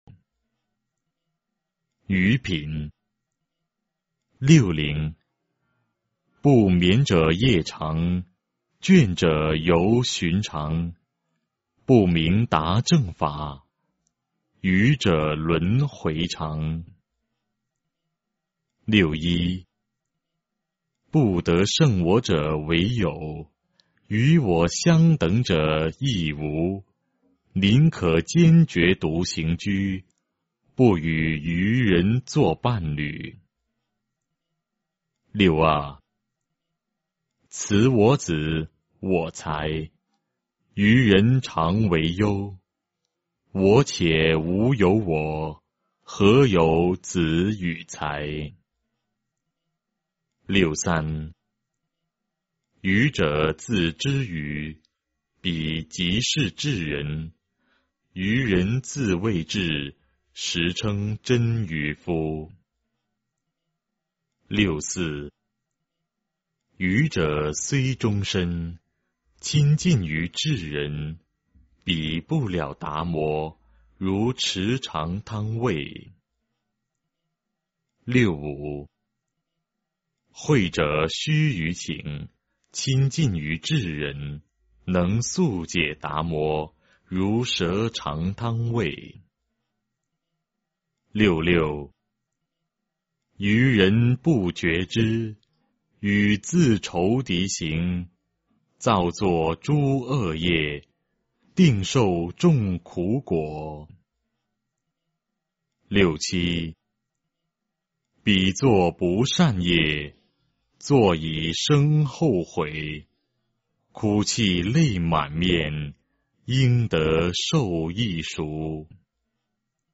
法句经-愚人品 诵经 法句经-愚人品--未知 点我： 标签: 佛音 诵经 佛教音乐 返回列表 上一篇： 法句经-象品 下一篇： 金光明经-02-念诵 相关文章 印光法师文钞14 印光法师文钞14--净界法师...